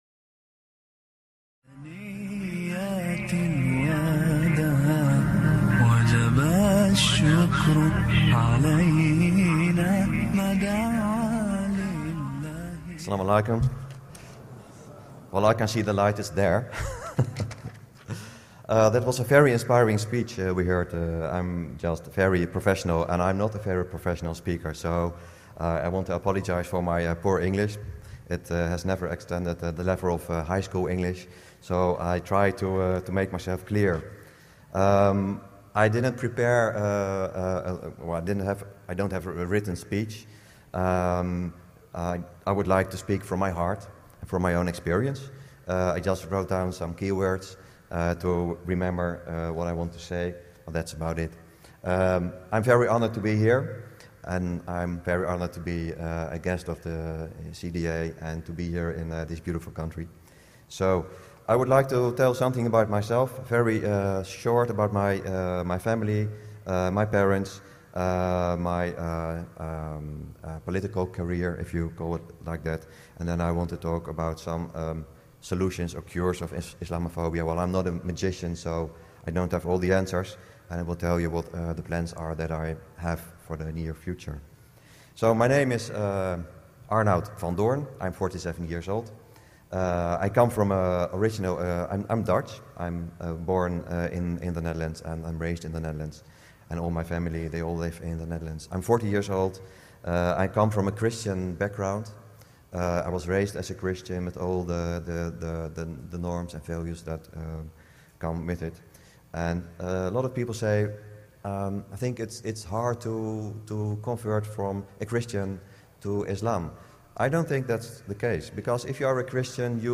Amazing speech by Arnoud Van Doorn (the former prominent Islamophobe accepted Islam) at the ICNA Canada “Carry the Light 2013” convention.